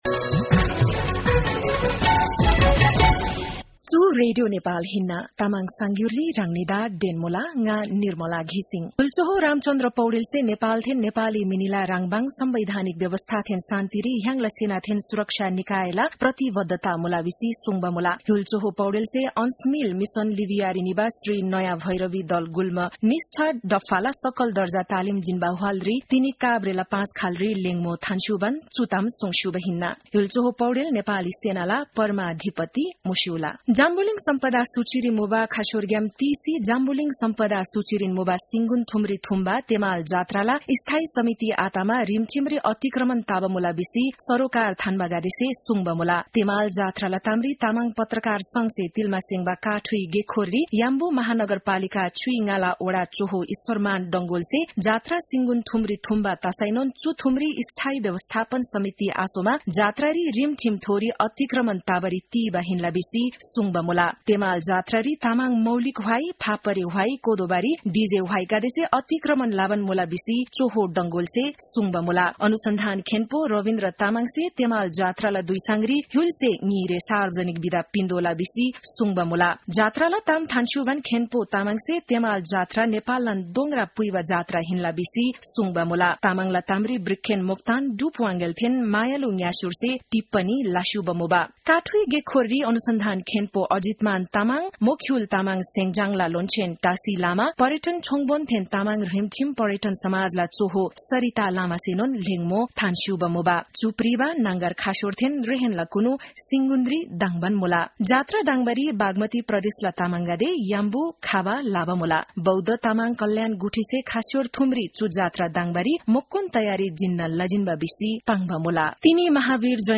तामाङ भाषाको समाचार : २८ चैत , २०८१